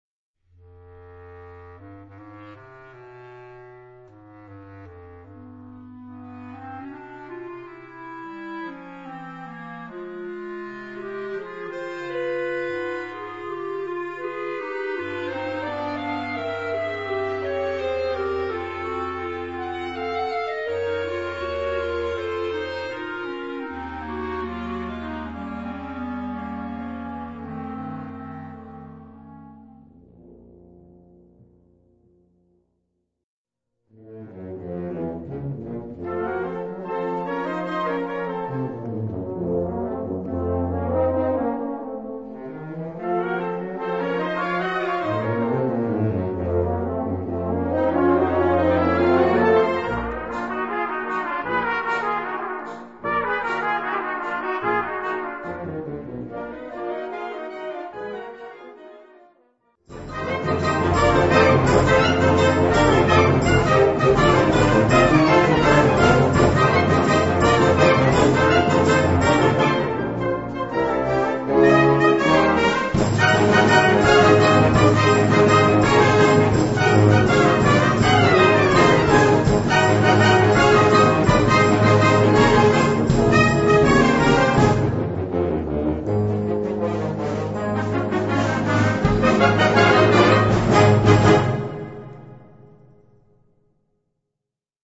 Catégorie Harmonie/Fanfare/Brass-band
Sous-catégorie Rhapsodies
Instrumentation Ha (orchestre d'harmonie)